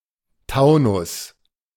The Taunus (German pronunciation: [ˈtaʊnʊs]
De-Taunus.ogg.mp3